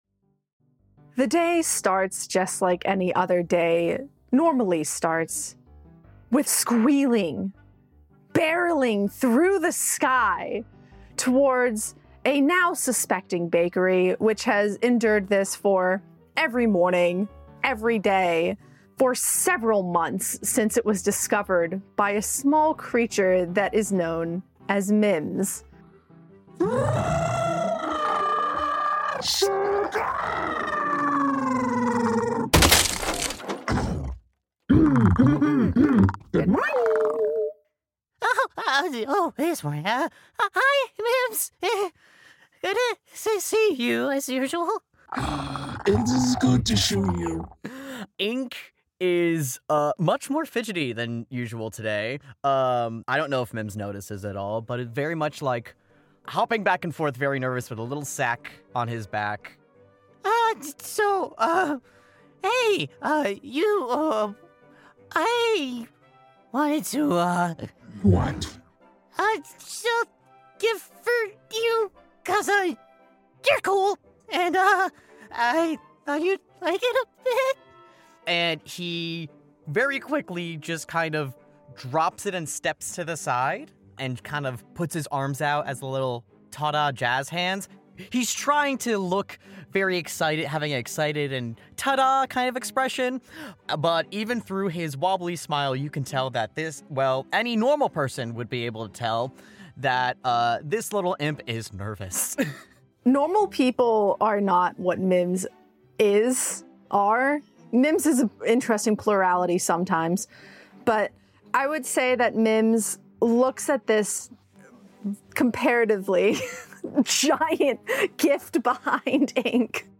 Content Warnings: Slimy Monstrous Noises, Unintentional Ingestion of Drugs, Descriptions of Said Drug Experiences, A Date Gone Weird or a Non-Date Gone Date-Adjacent?